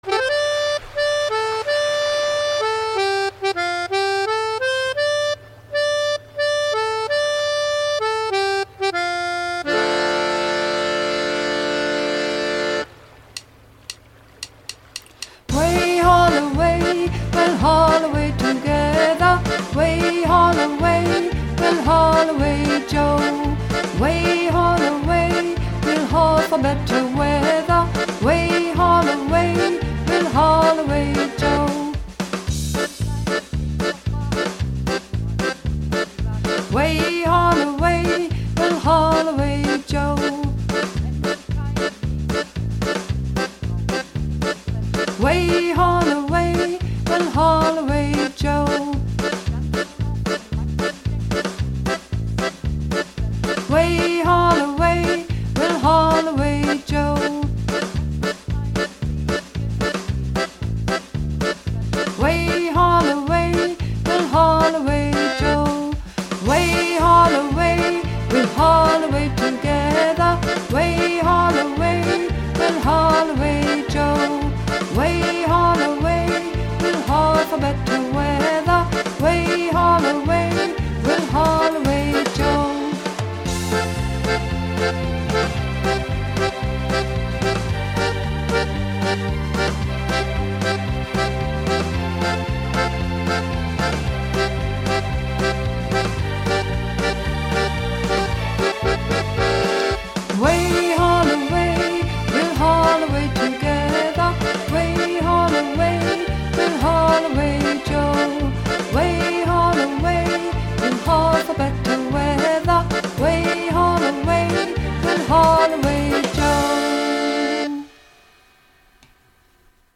Übungsaufnahmen - Haul Away Joe